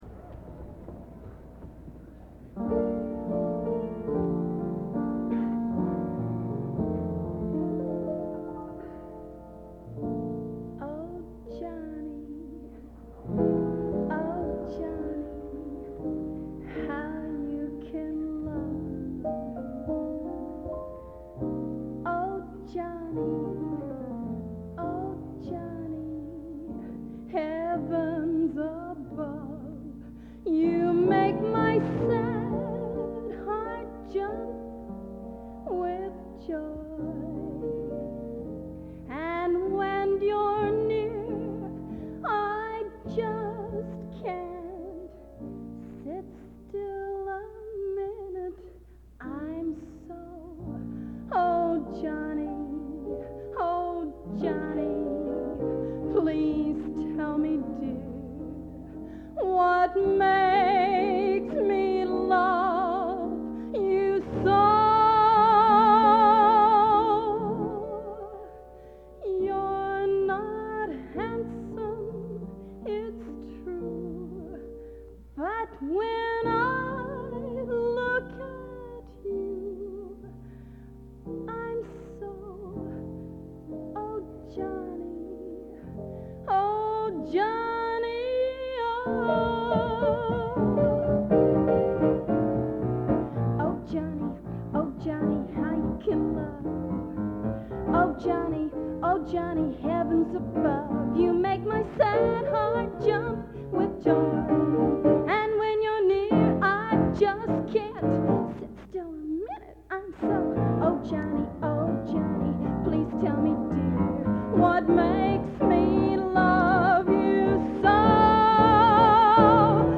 Genre: Popular / Standards | Type: Solo